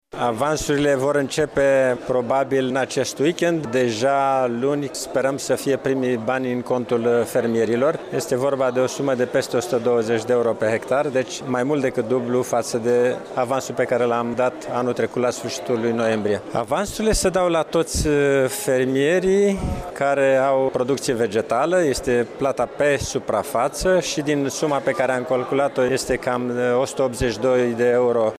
De luni încep plăţile pentru fermieri în contul anului agricol 2016, a anunţat astăzi, la Iaşi, ministrul Agriculturii, Achim Irimescu.